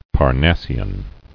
[Par·nas·si·an]